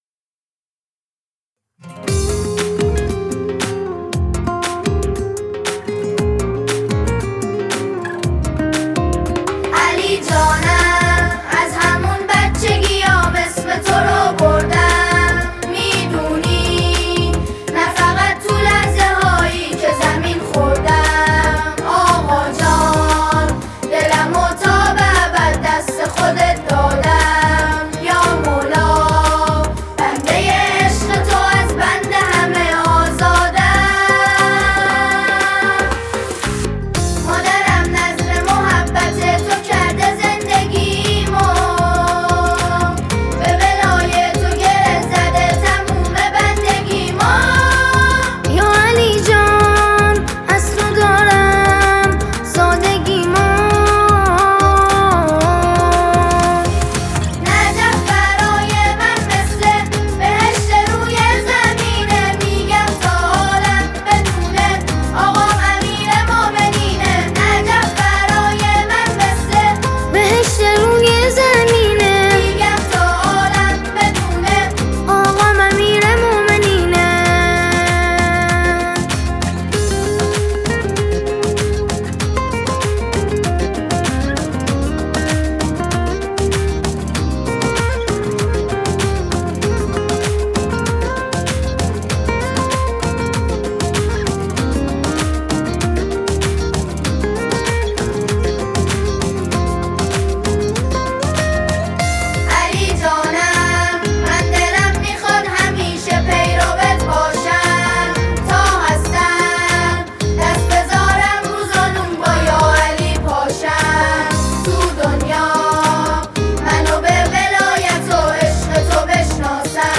پسران